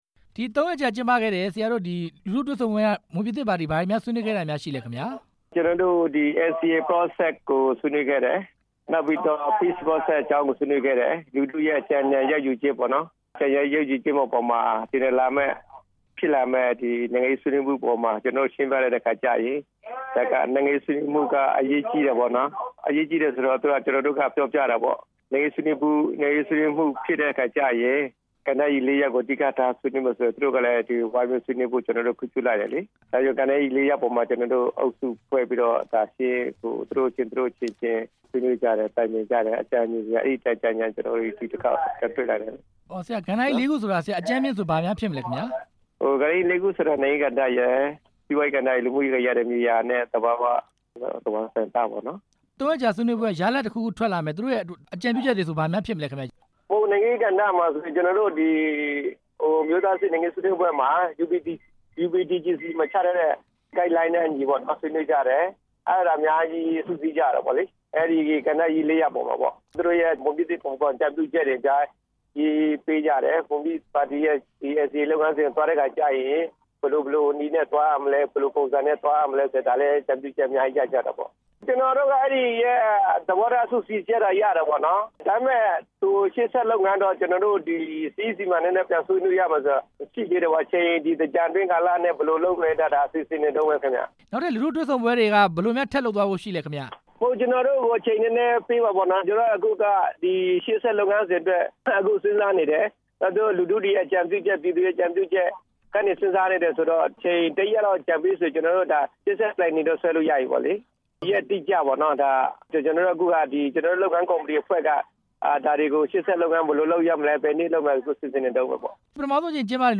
မွန် လူထုတွေ့ဆုံပွဲ မေးမြန်းချက်